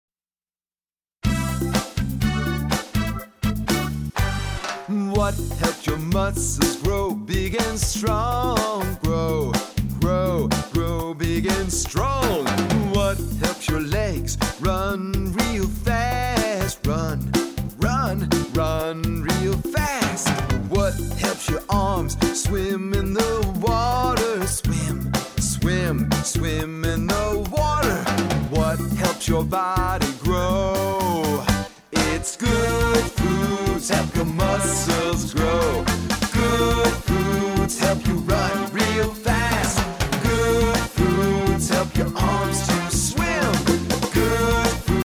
a children’s chorus